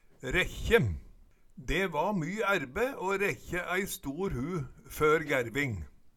Høyr på uttala Ordklasse: Verb Kategori: Handverk (metall, tre, lær) Attende til søk